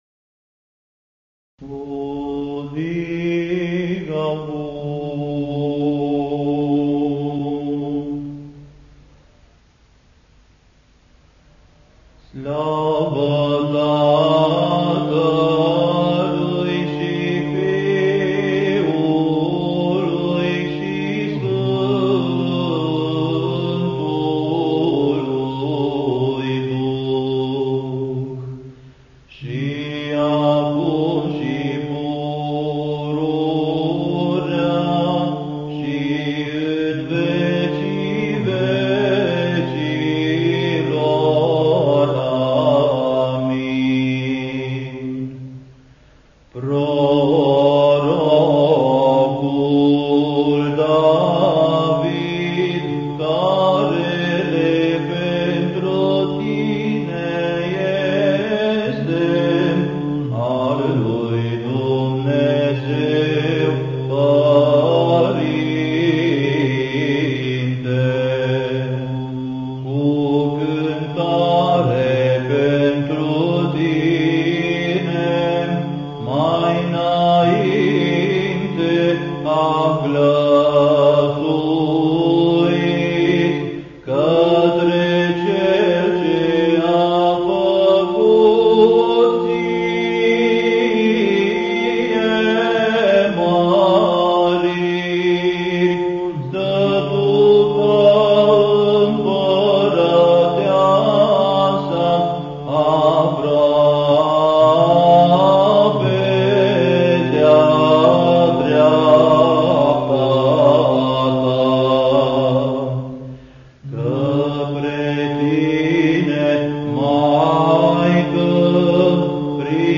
Index of /INVATAMANT/Facultate Teologie pastorala/Muzică bisericească și ritual/Dogmaticile
04. Dogmatica glasul 4.mp3